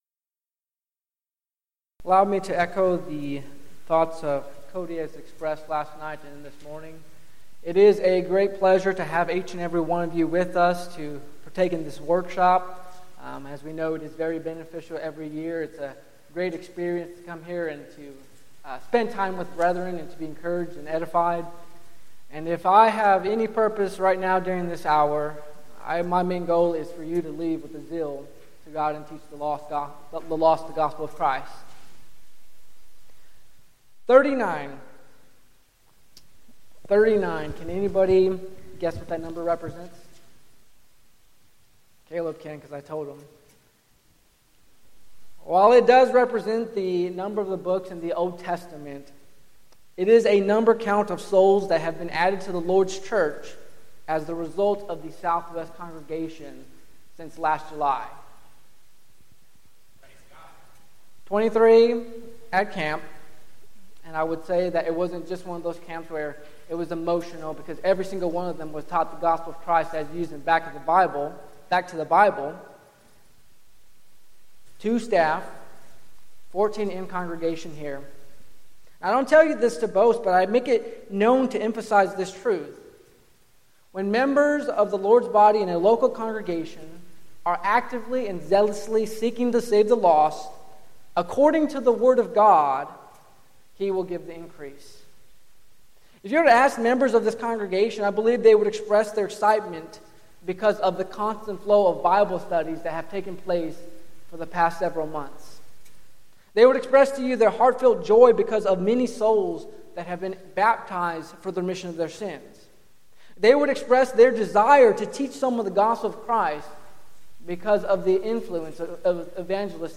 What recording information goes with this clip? Event: 6th Annual Southwest Spiritual Growth Workshop